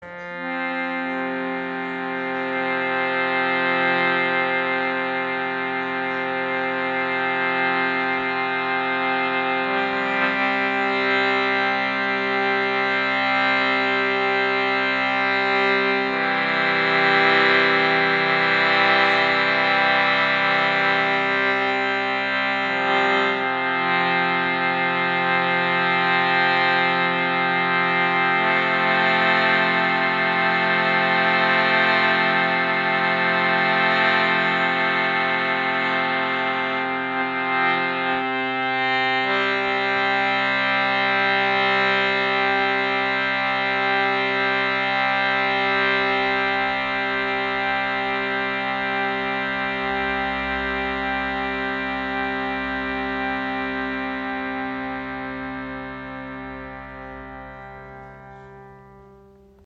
Teakholz Shruti Box | Gross dunkel | Tonumfang C3–C4 in 440 Hz
Die Shruti Box aus Teakholz erzeugt einen warmen, tragenden Bordunklang – ideal für Gesang, Meditation und Klangarbeit.
Der Ton lässt sich sowohl laut und sehr leise spielen und dabei gleichmässig halten.
Mit ihrer warmen Klangfarbe ist sie der absolute Favoriten unter den Shruti Boxen.
• Tonumfang: C3-C4
• Stimmung: 440 Hz oder in 432 Hz